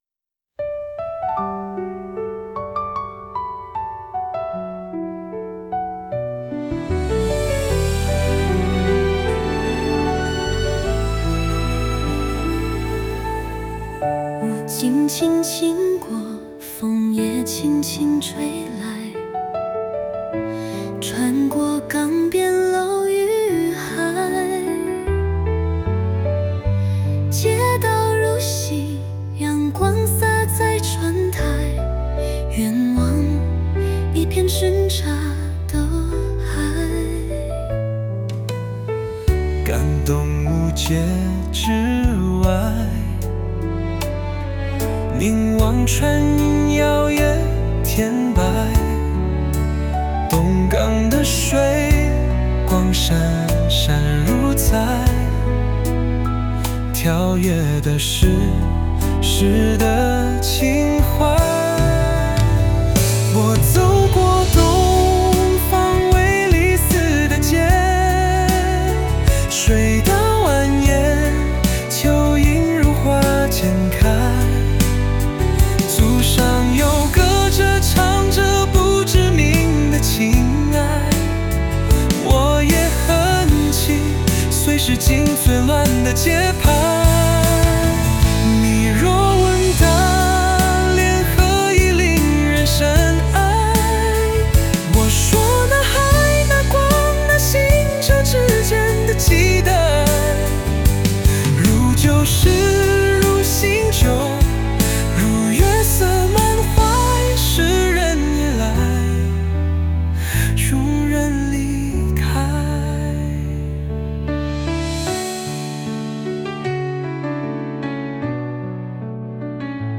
但這首不成熟的小作品，居然有用心的同學把它譜曲、編曲、錄製成了一首歌曲。 曲和演唱都非常美， 就是歌詞比較差了一些。